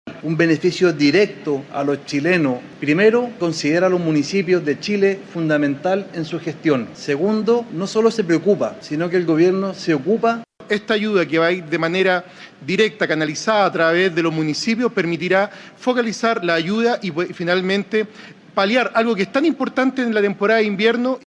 En ese sentido, el alcalde de Zapallar, Gustavo Alessandri, afirmó que esto da cuenta de la relevancia de los gobiernos comunales, mientras que su par de Paine, Rodrigo Contreras, subrayó que permitirá canalizar las ayudas de manera más focalizada hacia quienes realmente lo necesitan.